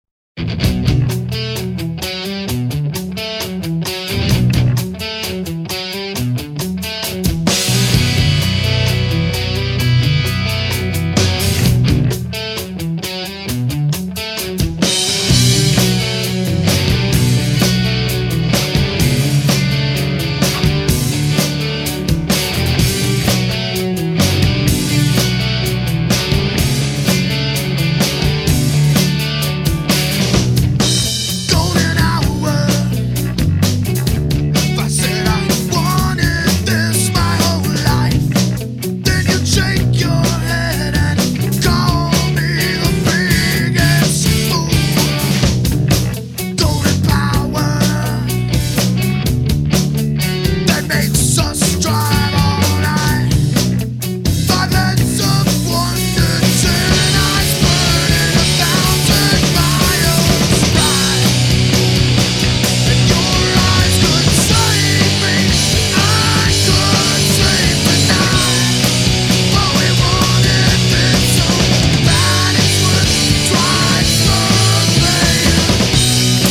GA are a twin guitar quintet with a razor-sharp